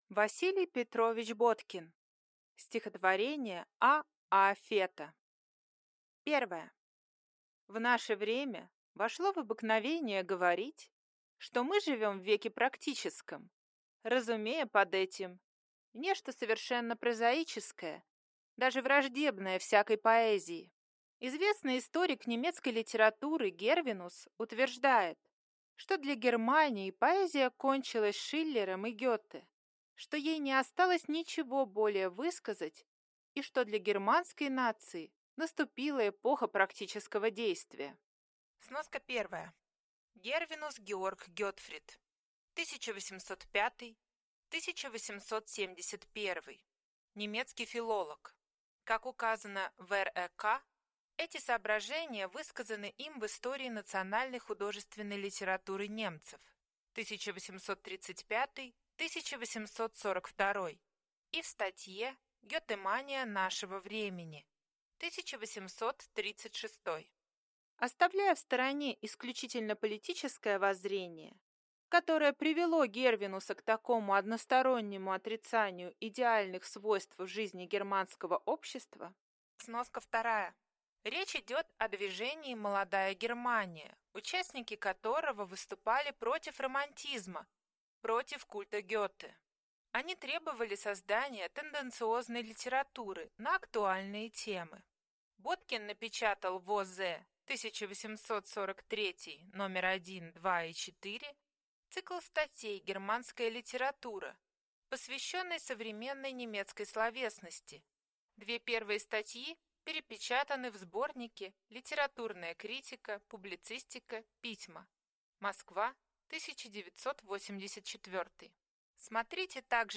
Аудиокнига Стихотворения А. А. Фета | Библиотека аудиокниг